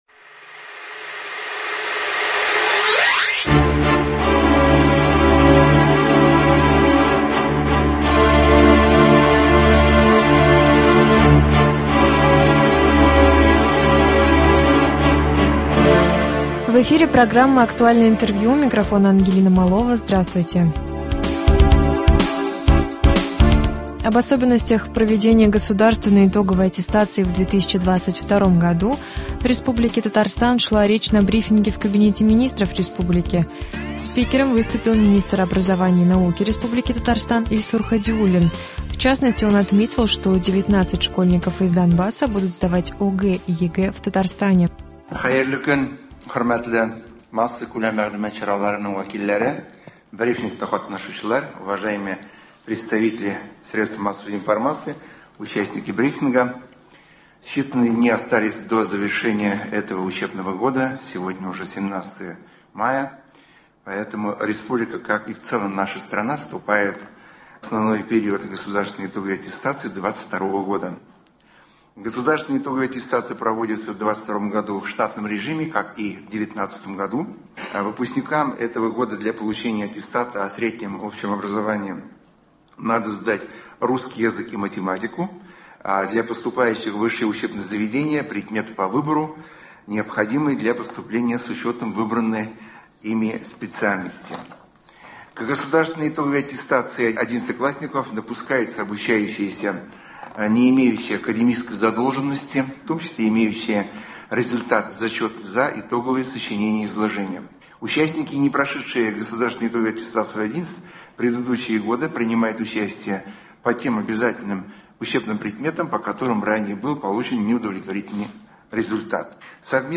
Об особенностях проведения государственной итоговой аттестации в 2022 году в Республике Татарстан шла речь на брифинге в Кабинете министров РТ. Спикером выступил Министр образования и науки РТ Ильсур Хадиуллин. В частности он отметил, что 19 школьников из Донбасса будут сдавать ОГЭ и ЕГЭ в Татарстане.